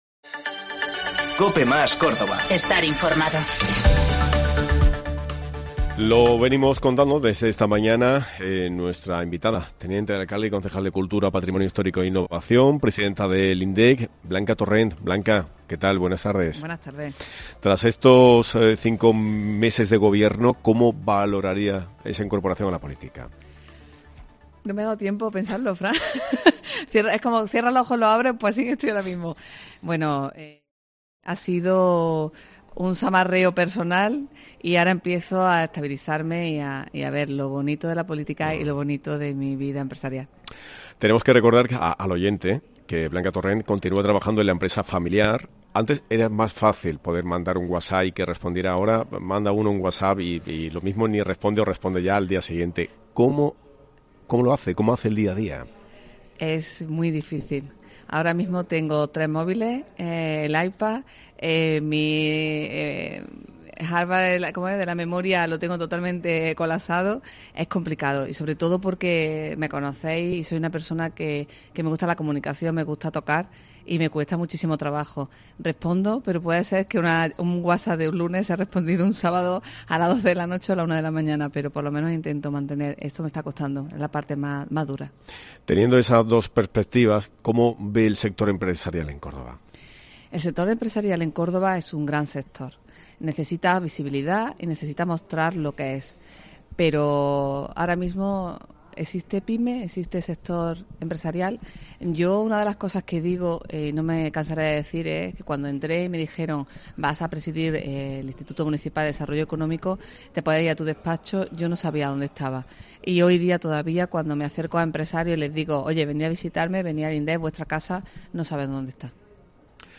Por los micrófonos de COPE ha pasado Blanca Torrent, presidenta del IMDEEC
Entrevista Blanca Torrent, concejal de Cultura, Patrimonio Histórico e Innovación y presidenta del IMDEEC